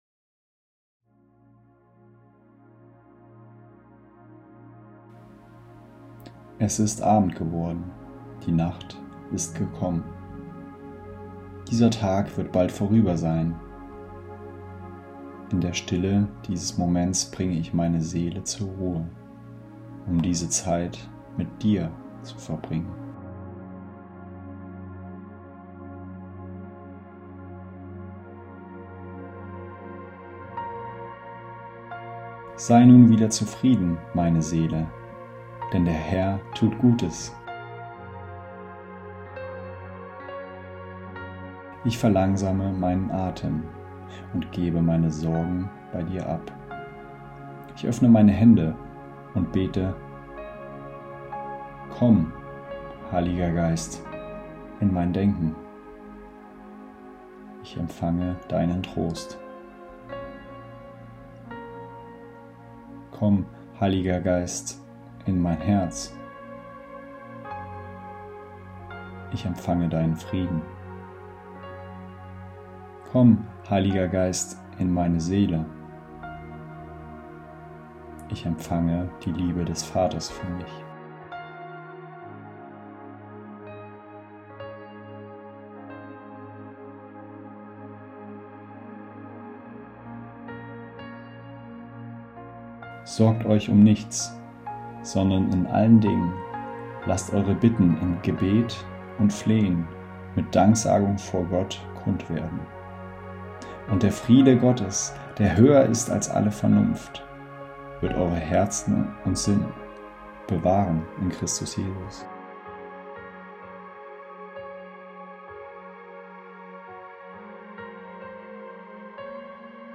Meditation 24.01.25
Abendmeditation_19.mp3